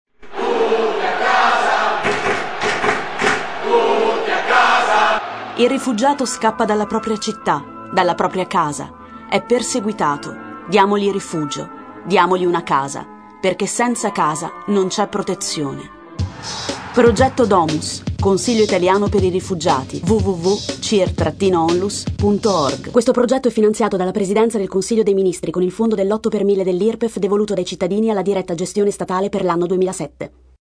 Per la Campagna sono stati realizzati il logo e l’immagine coordinata; materiali editoriali (brochure, cartellina convegno, flayer bus, segnalibri e locandina) e lo Spot radio.